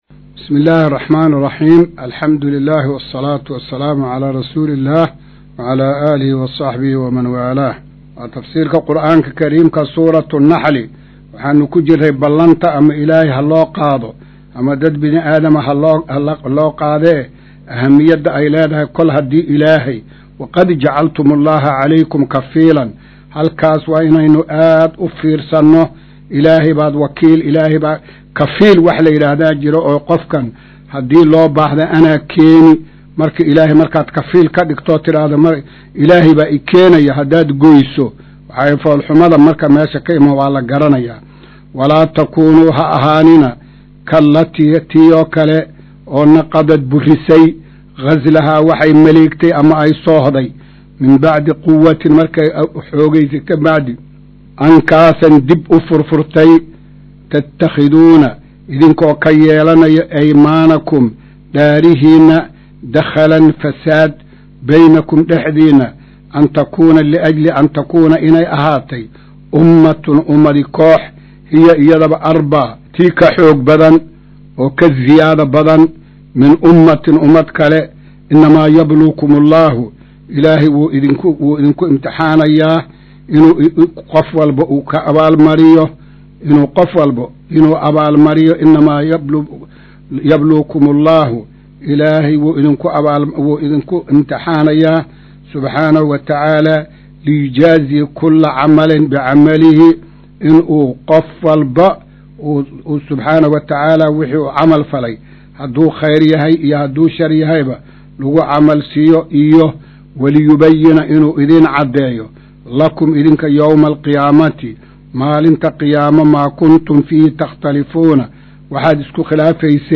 Maqal:- Casharka Tafsiirka Qur’aanka Idaacadda Himilo “Darsiga 135aad”